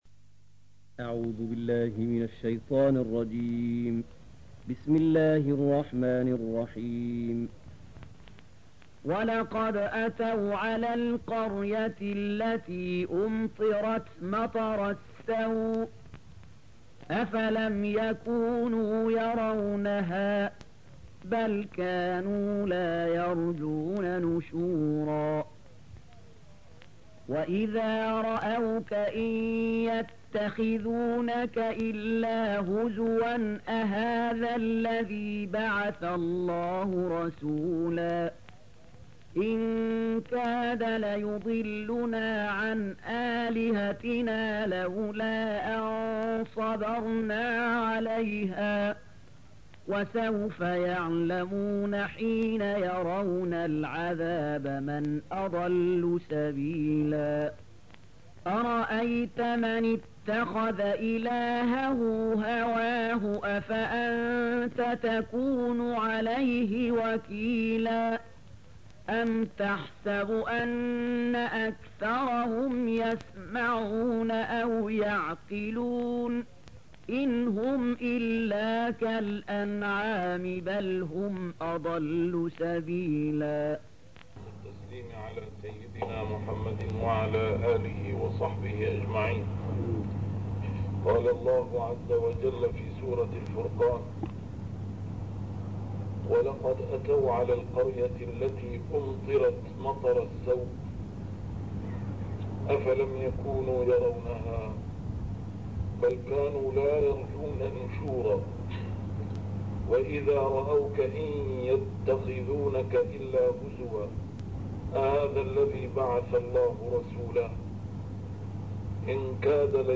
A MARTYR SCHOLAR: IMAM MUHAMMAD SAEED RAMADAN AL-BOUTI - الدروس العلمية - تفسير القرآن الكريم - تسجيل قديم - الدرس 212: الفرقان 40-44